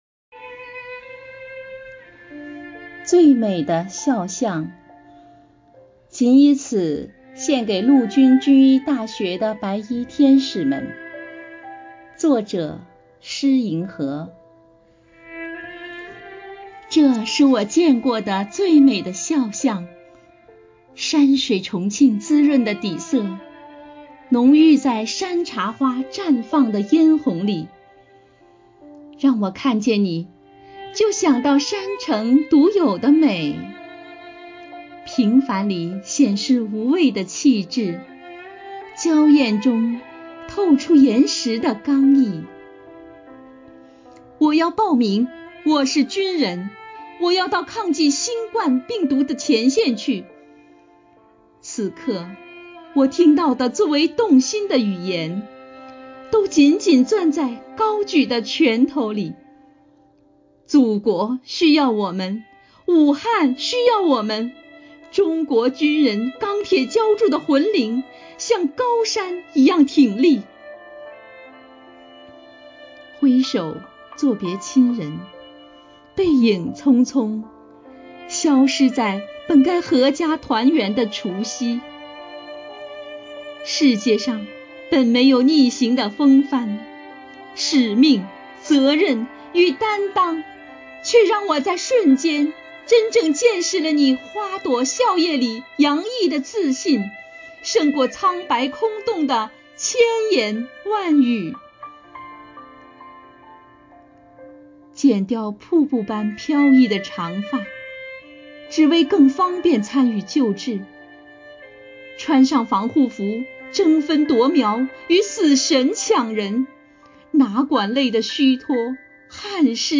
为赞美“逆行英雄”甘于奉献、大爱无疆的崇高精神，女教师们精心创作或挑选朗诵诗歌作品，用饱含深情的声音歌颂和致敬奋斗在一线的抗“疫”英雄们，讴歌了中华民族的伟大和坚韧，表达了对祖国和武汉的美好祝福，热切盼望疫情过后的春暖花开。
附件：一、抗“疫”事迹诗朗诵选登